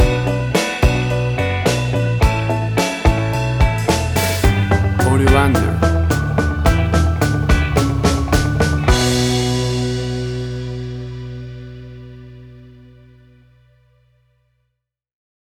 Tempo (BPM): 108